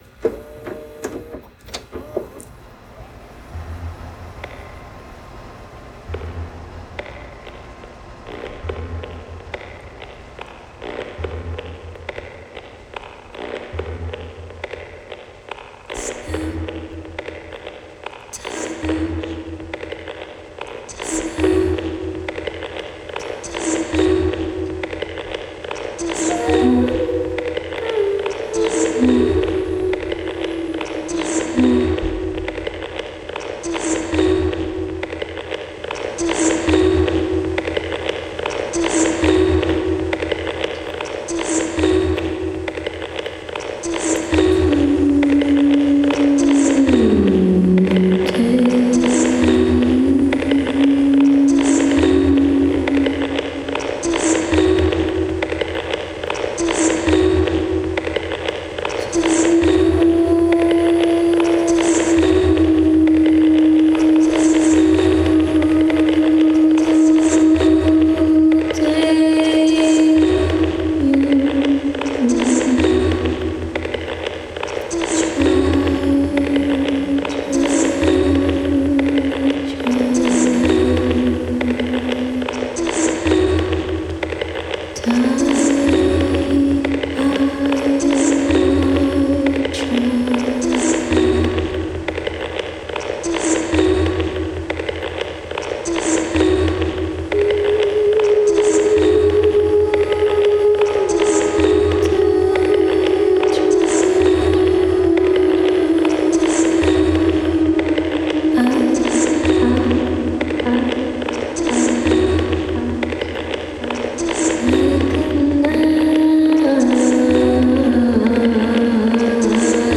Soundtrack commission for contemporary dance choreographer
Voice
Electronics
Field recordings